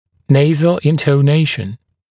[‘neɪzl ˌɪntəu’neɪʃn][‘нэйзл ˌинтоу’нэйшн]носовая интонация, манера говорить в нос